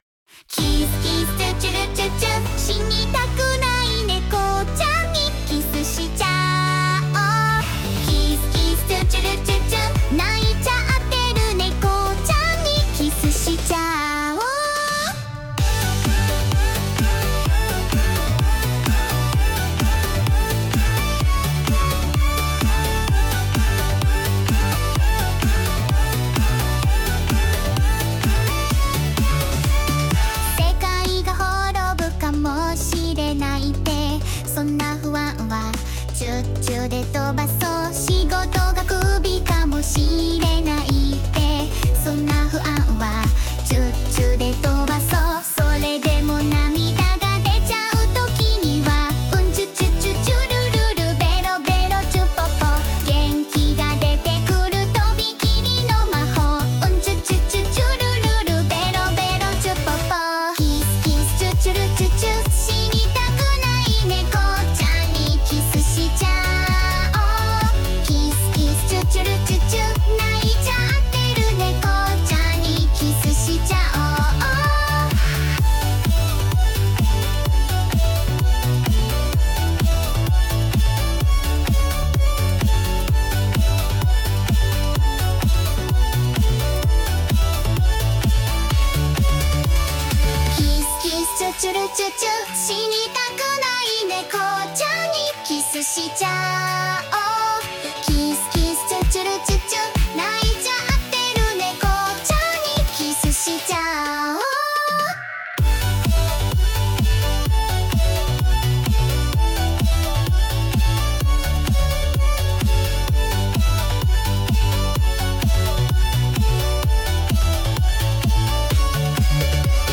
死にたくないネコちゃんにキスしちゃお💋
キス音が汚いんぬ…